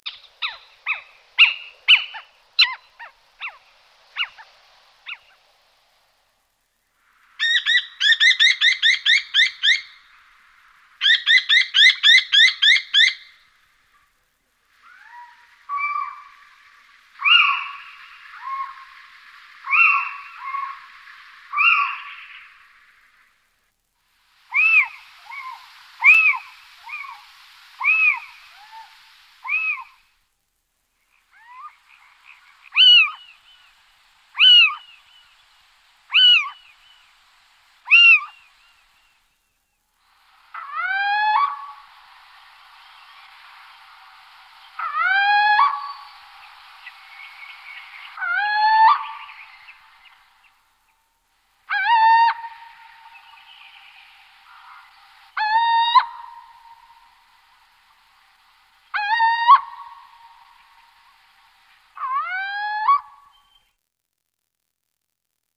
Der Steinkauz ist lebhaft und verfügt über ein breites Rufrepertoire. Am bekanntesten ist der von beiden Geschlechtern vorgetragene Reviergesang. Es ist ein monoton gereihtes ghuk, das beim Männchen meist in grelle Erregungslaute (guiau, kwiau) übergeht und mit schrillen miau- oder miji-Silben endet.
Rufe des Steinkauzes
Steinkauz.ogg